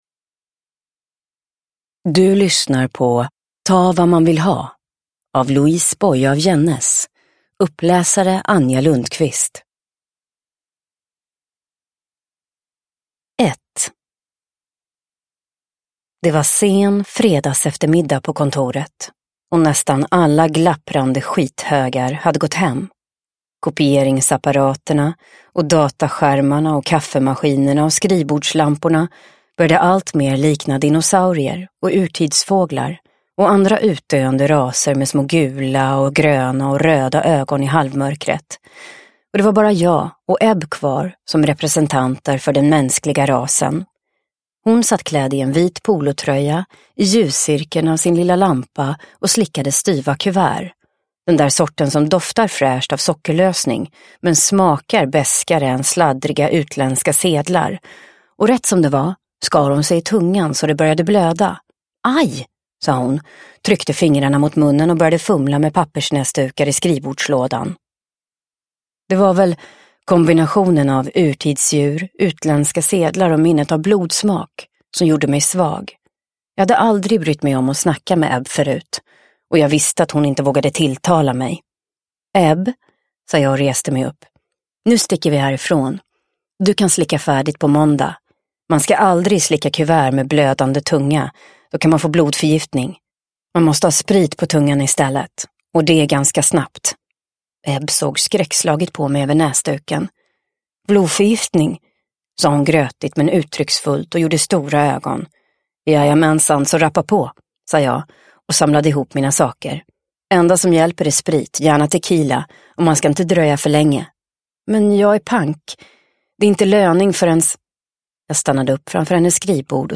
Uppläsare: Anja Lundqvist
Ljudbok